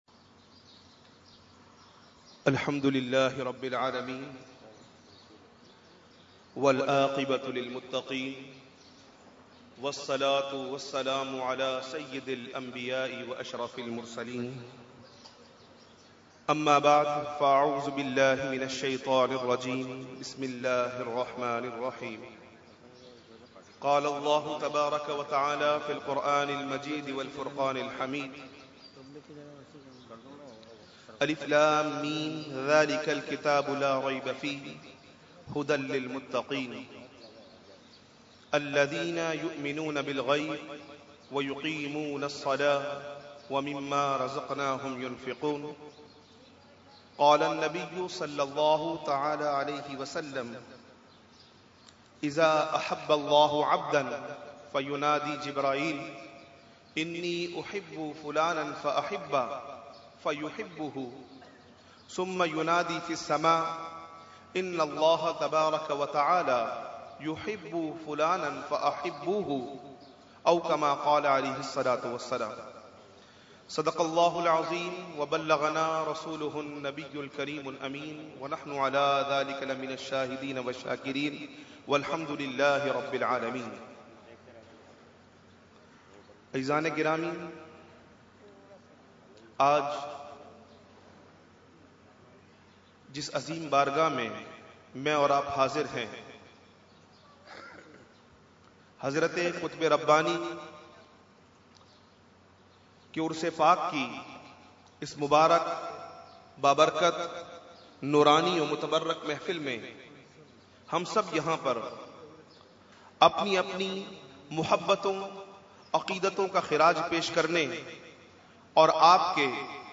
Category : Speech | Language : UrduEvent : Urs Qutbe Rabbani 2016